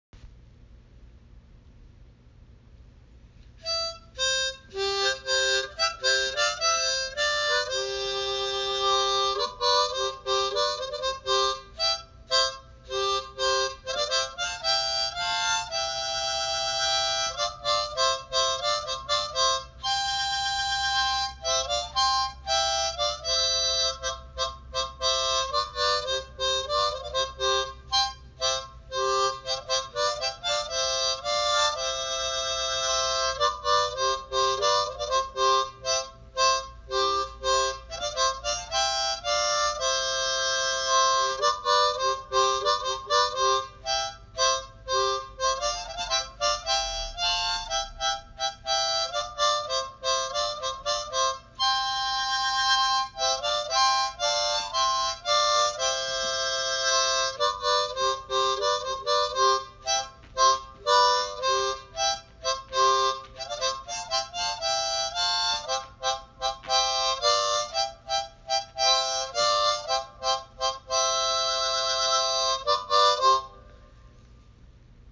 I-fly-away-hohner-harmonica-.mp3